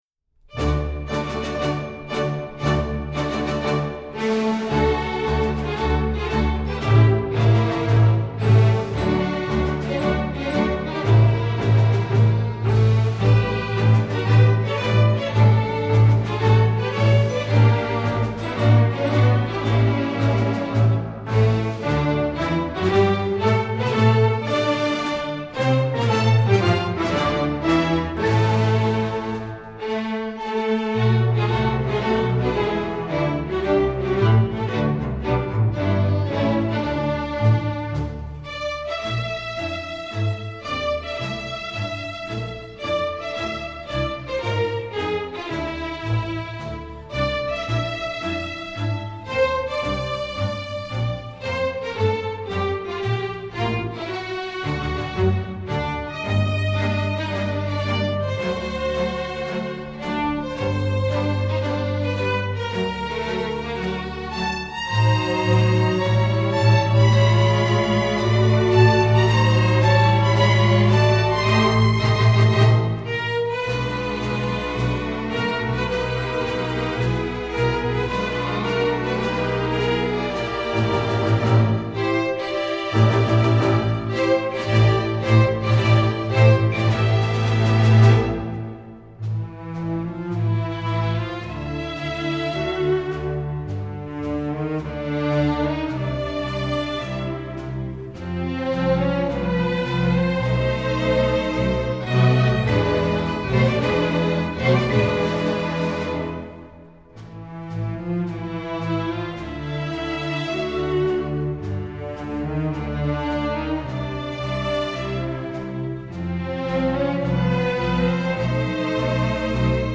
Instrumentation: full orchestra